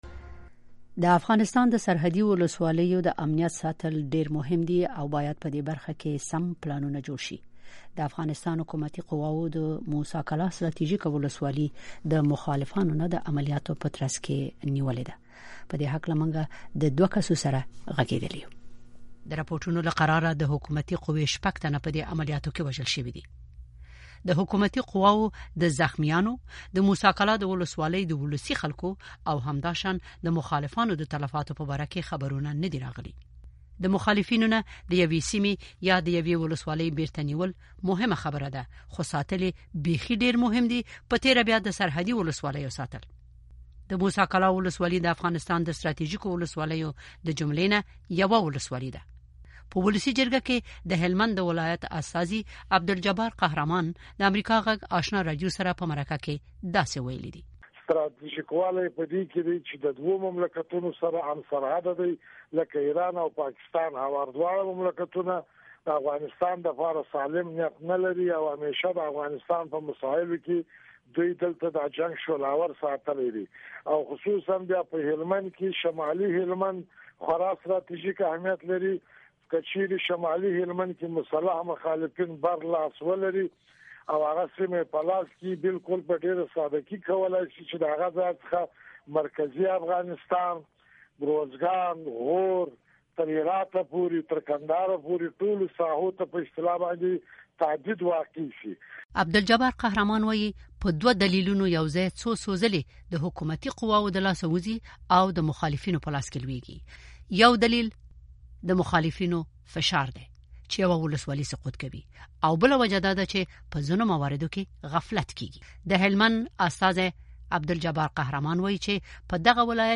مرکې
په پارلمان کې د هلمند د ولایت استازی عبدالجبار قهرمان وا یي، ددغه ولایت په شمالي سیمه کې د مخالفو وسلوالو تجمع د پاکستان پلان دی.